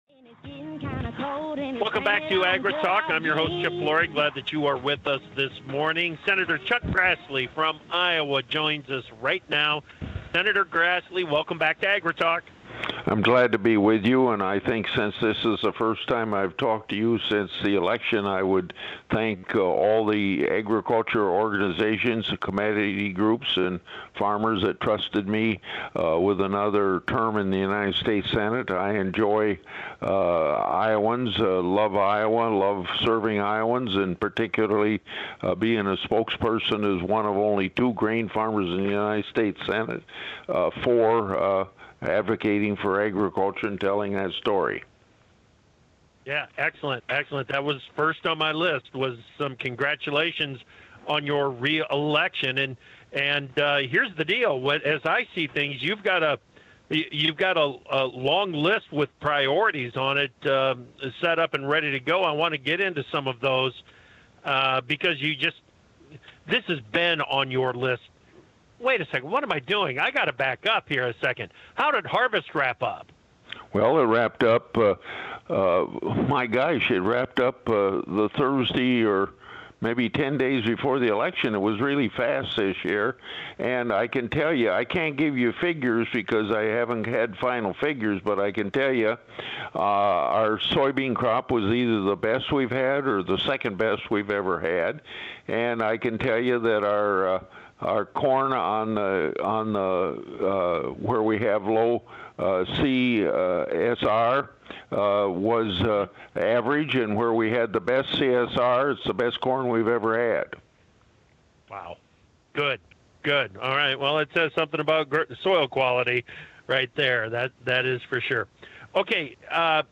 Grassley Interview